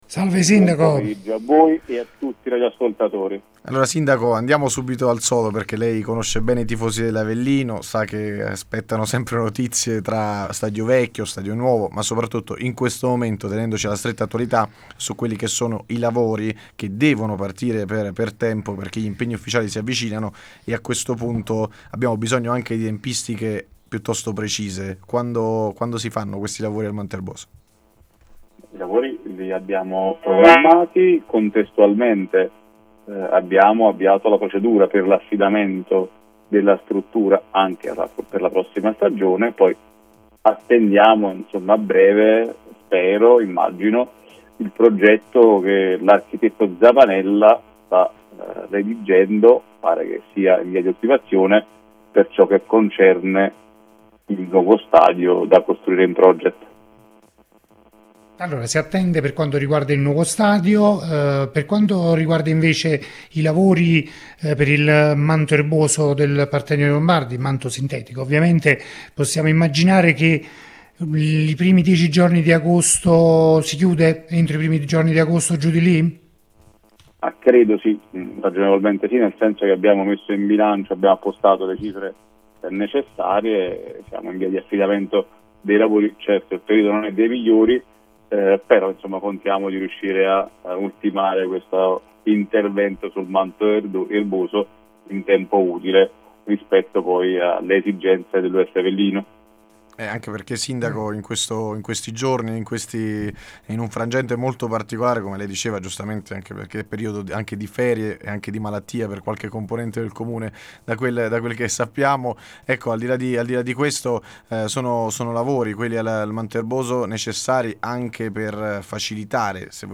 Ospite de Il Pomeriggio da Supereroi il sindaco di Avellino, Gianluca Festa, che ha fatto il punto sulla situazione attuale dello Stadio Partenio-Lombardi.